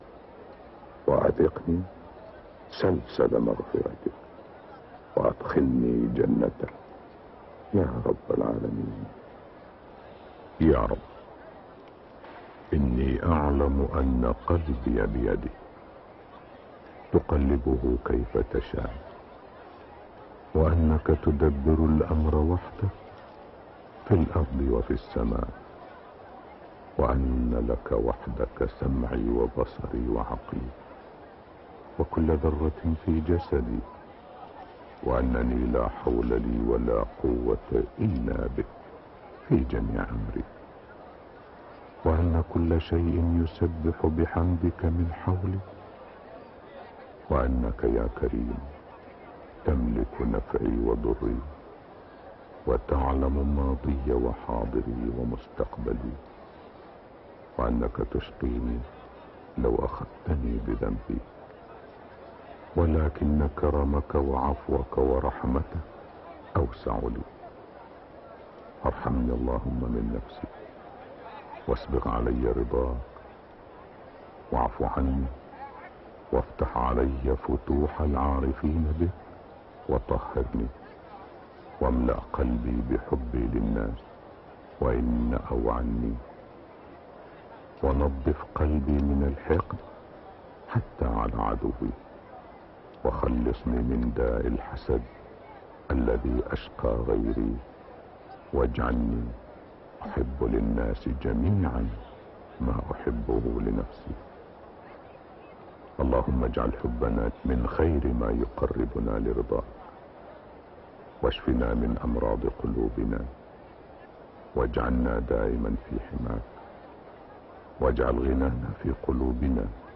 صلاة العشاء 5 شوال 1432هـ فواتح سورة الواقعة 1-56 > 1432 هـ > الفروض - تلاوات ماهر المعيقلي